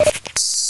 Звуки аптечки
Звук пополнения здоровья из Half-Life 2